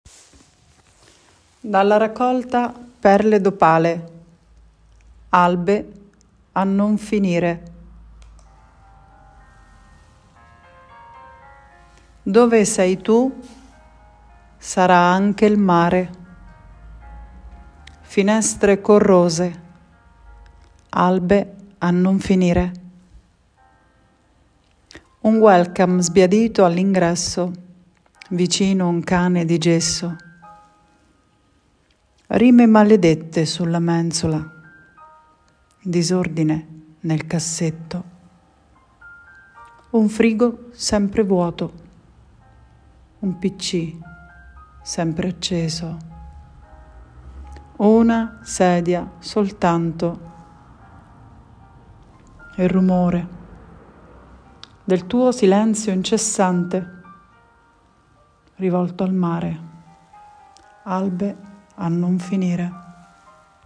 la poesia letta